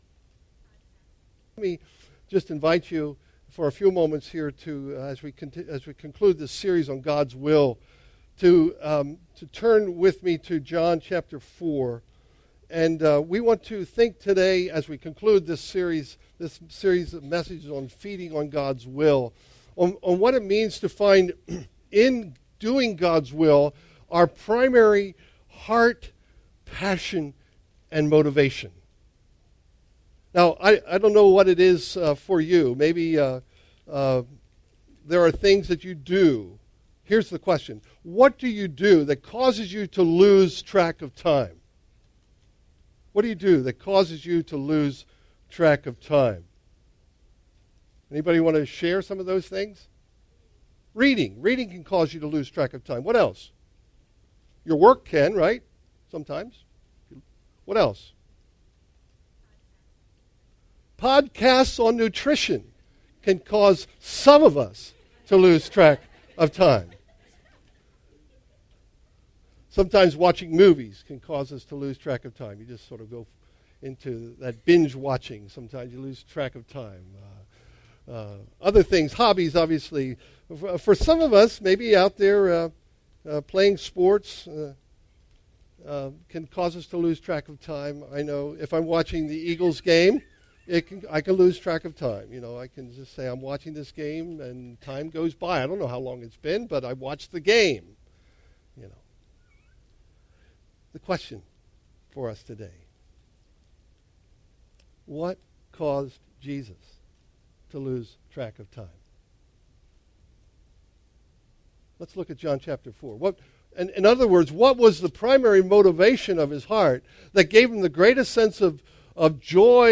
Sermon Series - July 01 - Discovering God's Will July 08 - Embracing God's Will July 15 - Fullfilling God's Will July 22 - Living God's Will July 29 - Feeding on God's Will